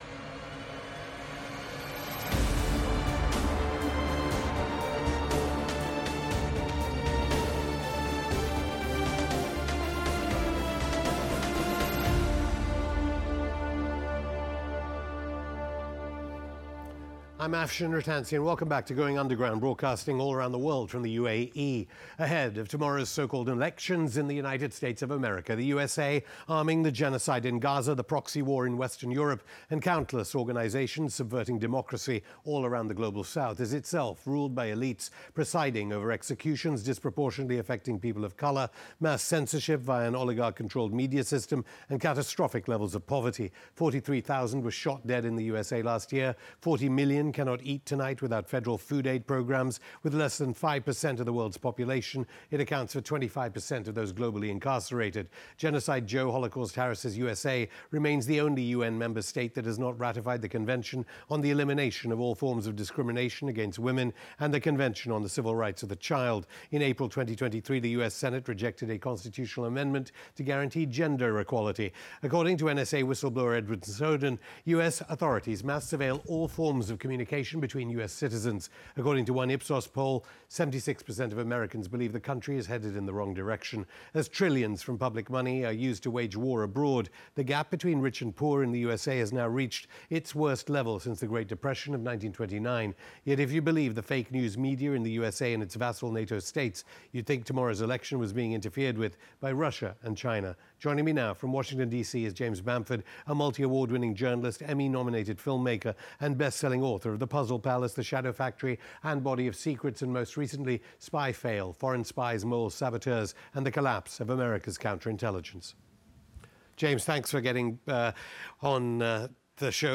Going Underground Hosted by Afshin Rattansi